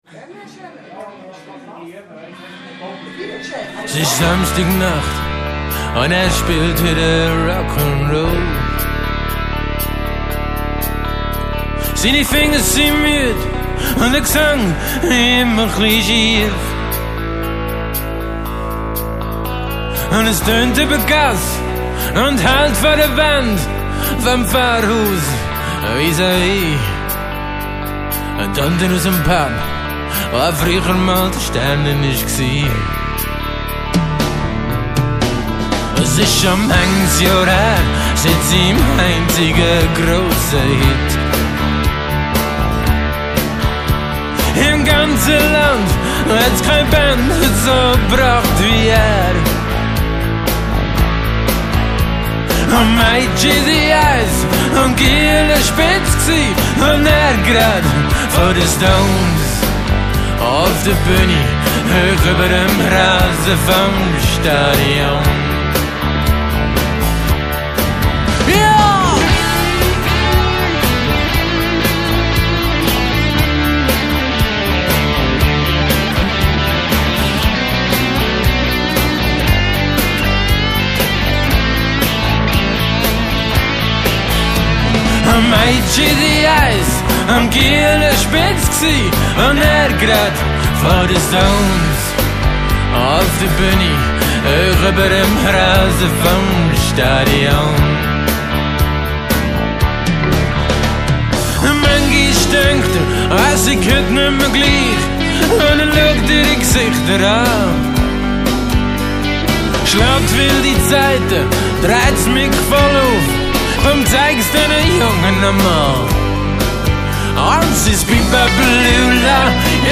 alles andere Homerecording
Schlagzeug
Bass
Solo-Gitarre
Synth-Bass, akustische Gitarren, E-Gitarren
Hammond B3
Chorgesang